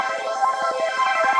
SaS_MovingPad03_170-C.wav